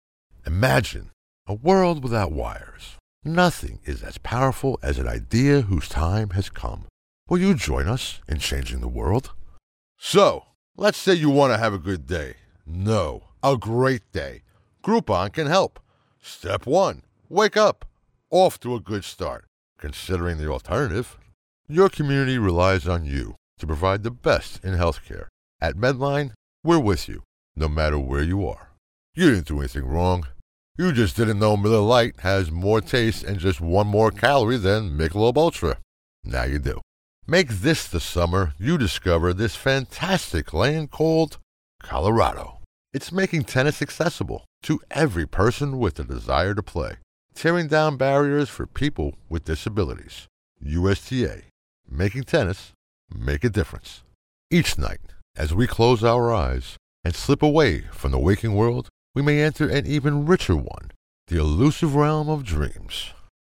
Voice is Deep, Distinctive, and Authoritative, with a Commanding Presence that exudes Confidence and Power.
Radio Commercials
Dry Demo Studio Quality